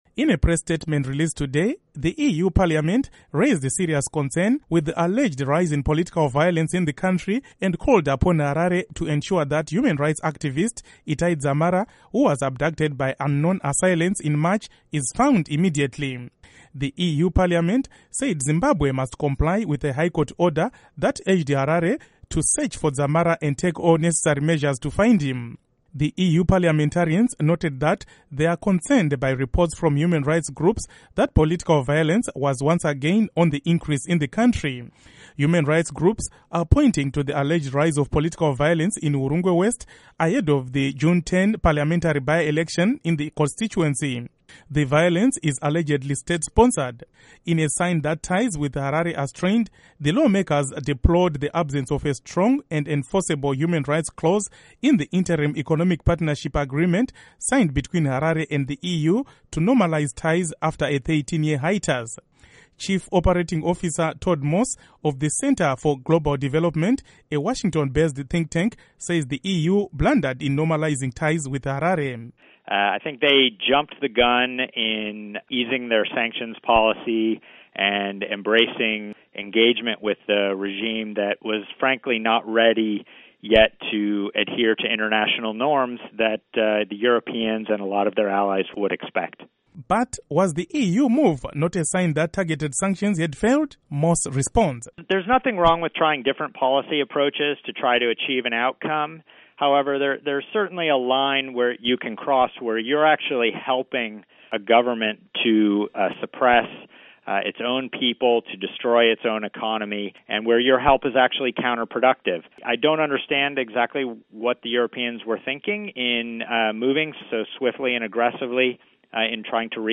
Report on Souring EU, Zimbabwe Relations